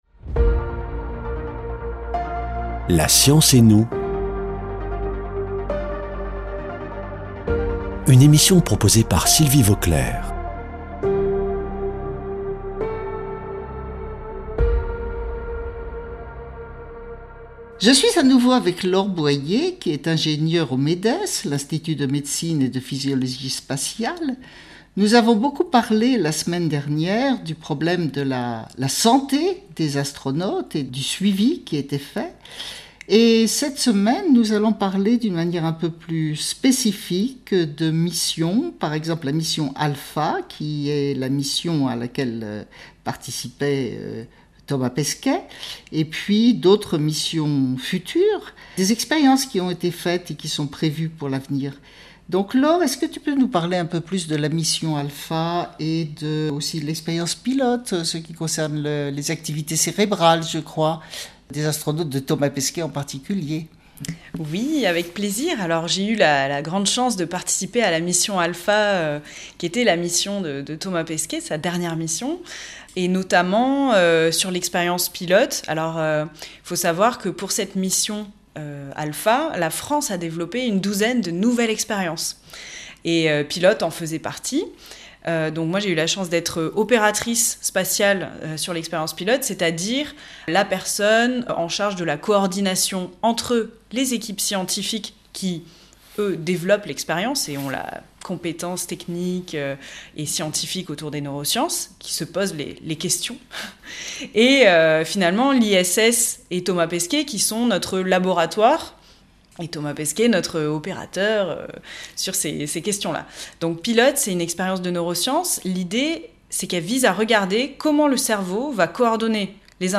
Une émission présentée par Sylvie Vauclair